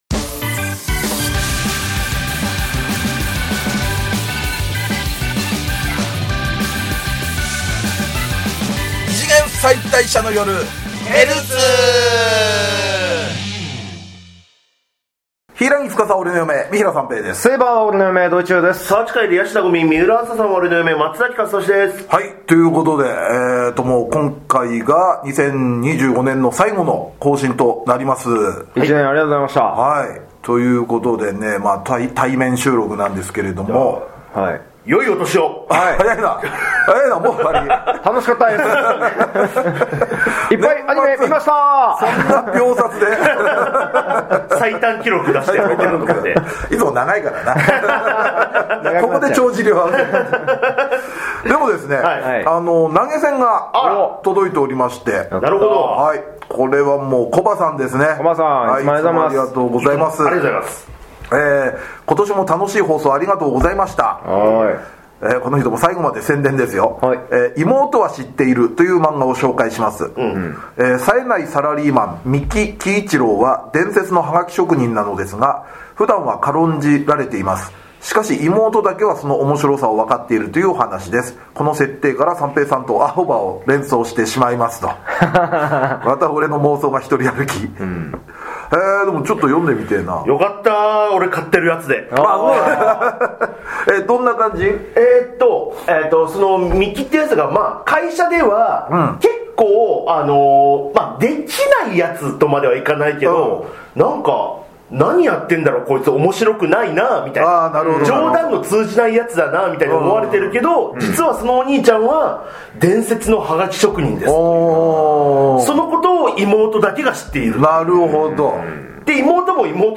旬なテレビアニメの感想話やオススメ漫画話で楽しく陽気にバカ話！ 二次元キャラクターを嫁に迎えた芸人３人による、キャラ萌え中心アニメトークポッドキャストラジオです！